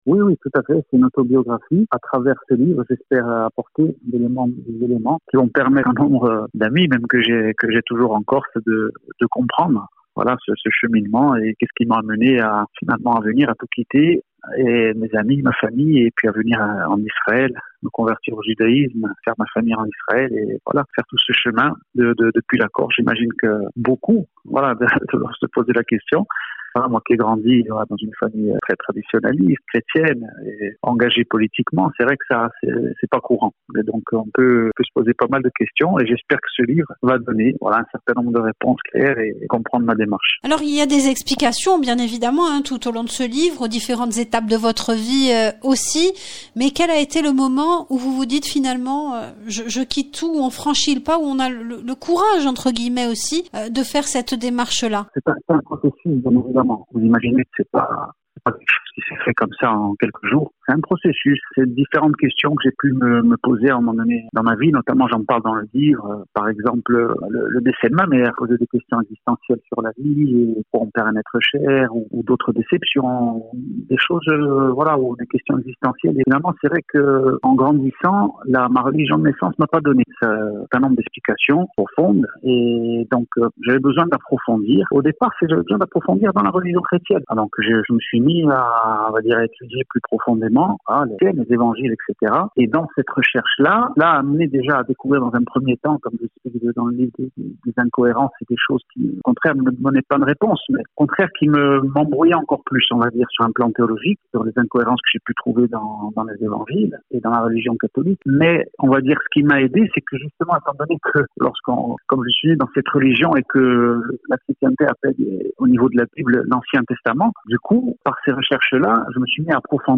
Interview Audio: